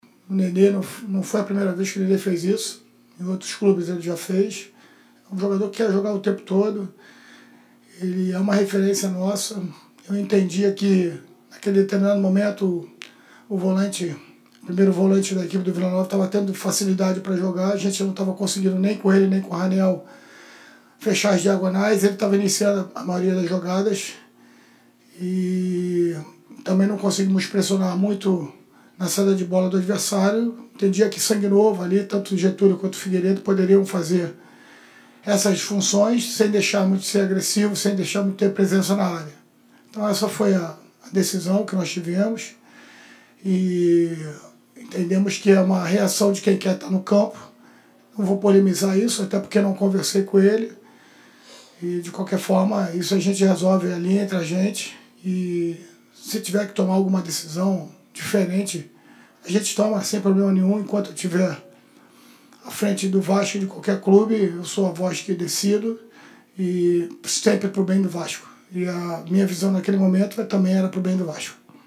entrevista coletiva